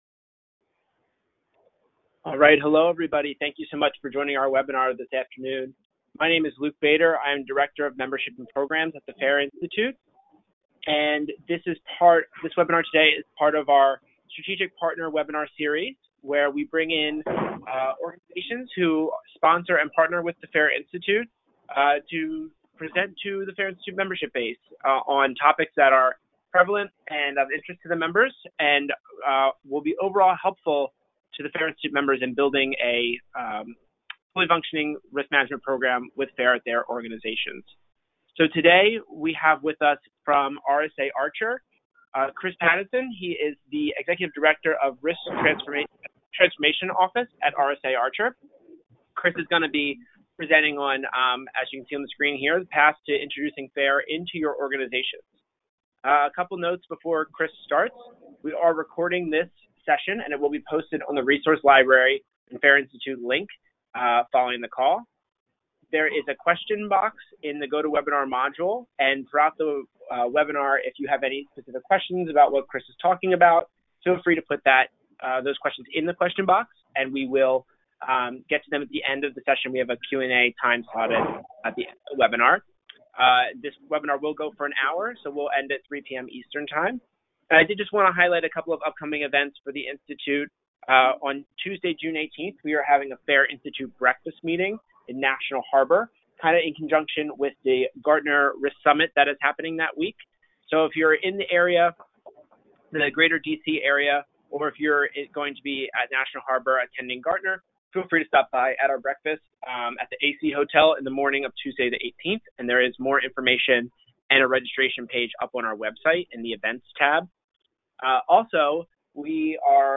An audio only extract and static slides are available for download here for listening while driving or running: